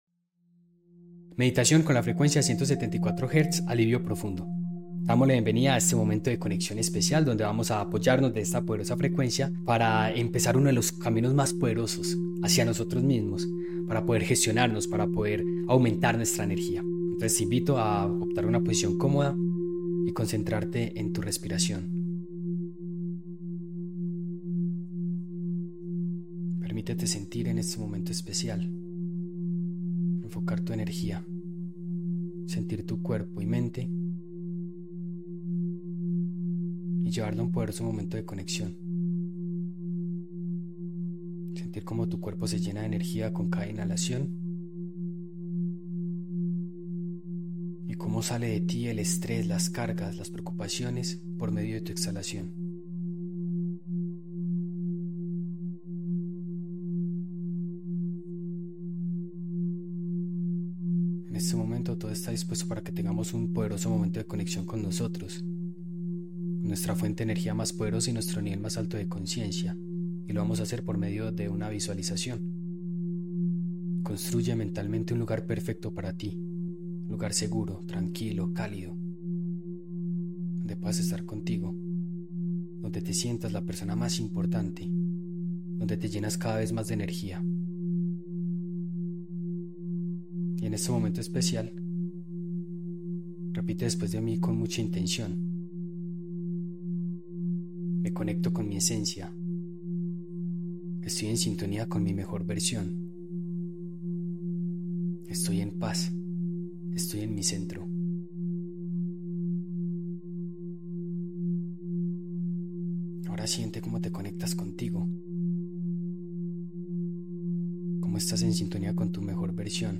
174 Hz Alivio Profundo Sumérgete en la vibración más baja del sistema Solfeggio, conocida por generar un efecto analgésico natural que ayuda a liberar el dolor físico y emocional. Esta meditación 8D corta te guía hacia un estado de calma, seguridad interior y descanso profundo, ideal para reconectar con tu centro y soltar tensiones.